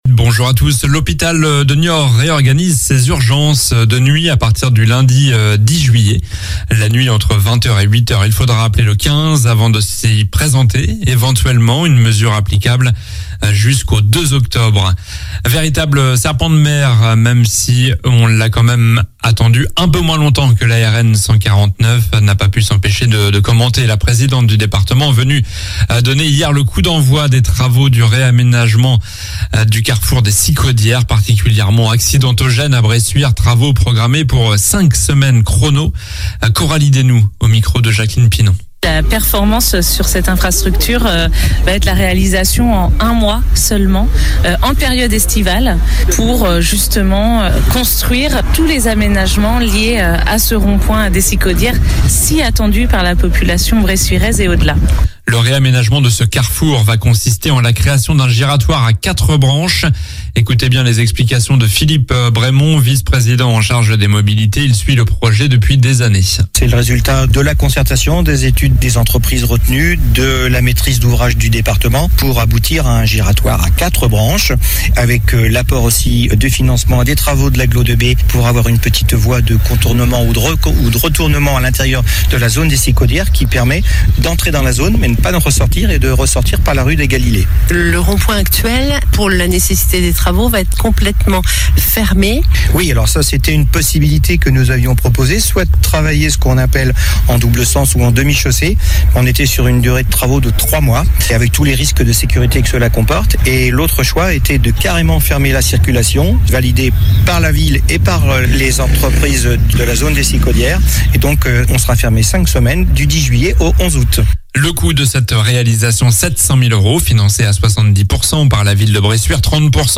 Journal du jeudi 6 juillet